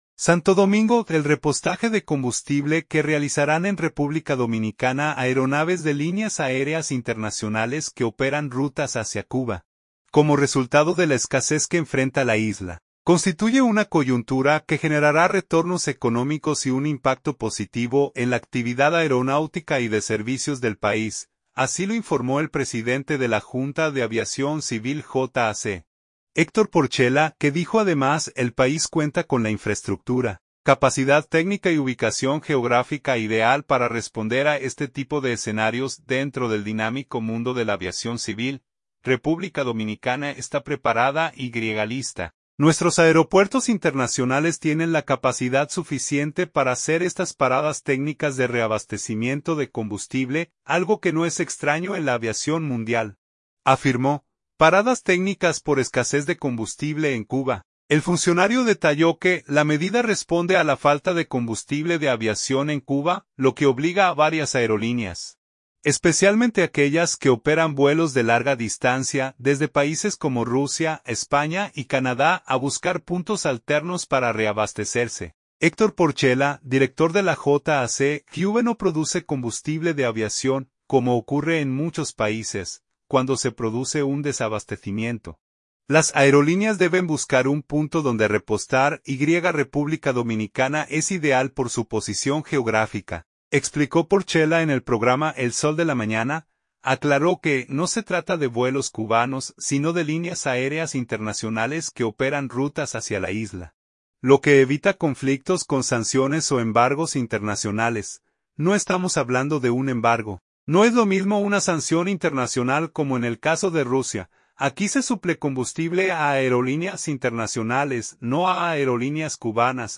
Héctor Porcella, director de la JAC.
“Cuba no produce combustible de aviación, como ocurre en muchos países. Cuando se produce un desabastecimiento, las aerolíneas deben buscar un punto donde repostar, y República Dominicana es ideal por su posición geográfica”, explicó Porcella en el Programa el Sol de la Mañana.